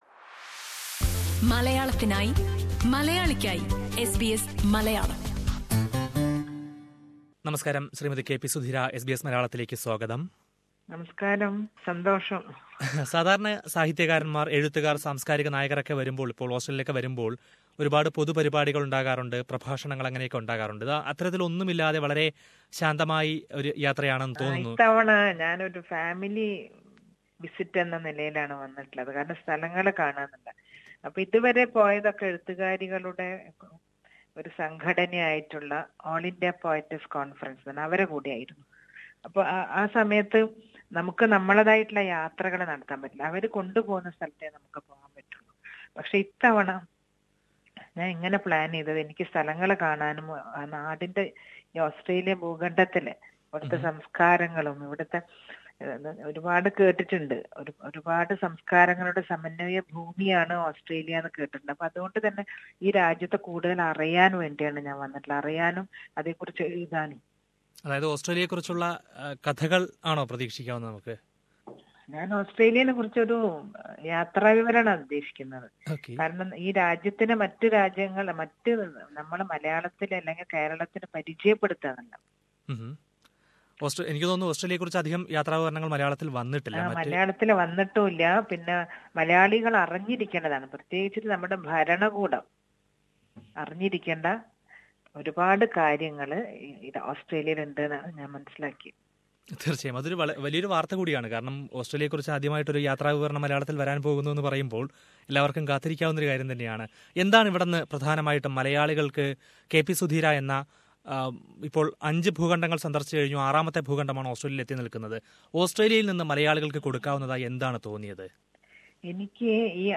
She has announced it while talking to SBS Malayalam Radio during her visit to Australia. Listen to the full interview here.